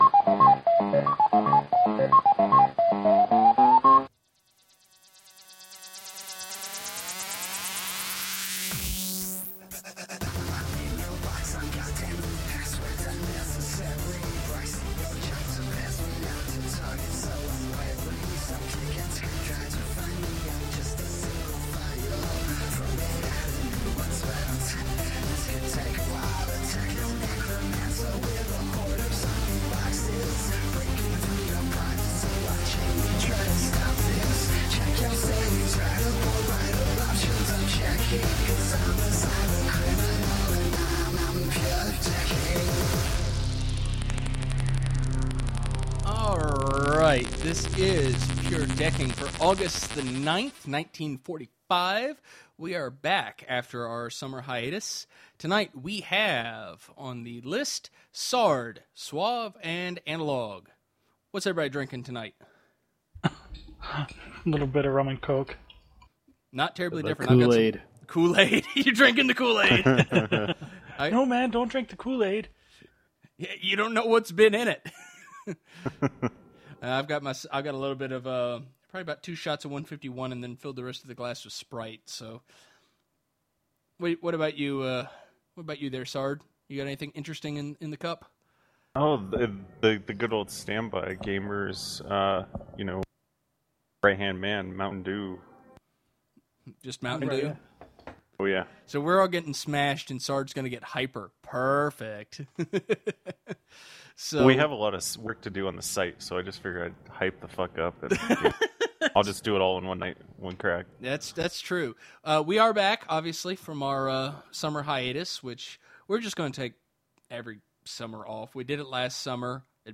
live show